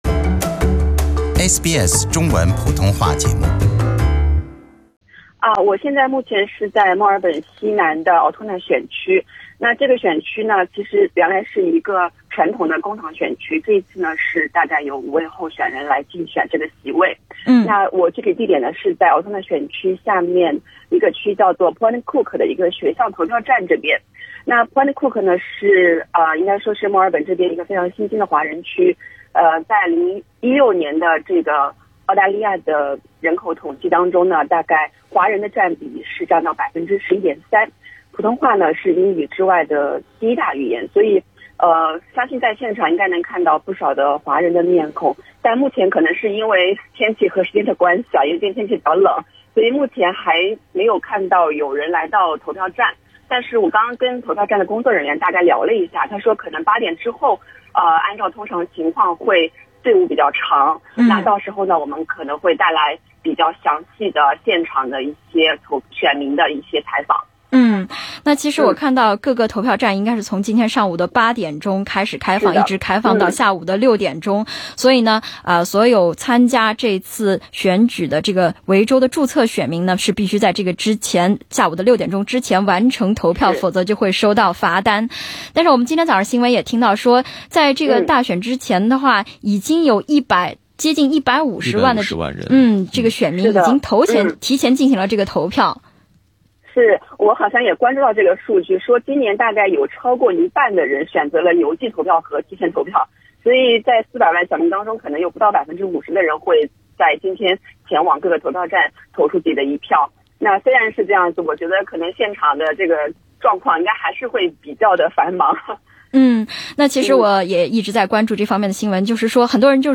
SBS普通话记者直击墨尔本西南部的Altona选区投票。
SBS普通话记者在选举日早间抵达Point Cook的一个投票站进行采访。
社会治安、生活成本和交通运输成为选举核心议题。 点击收听来自现场的报道。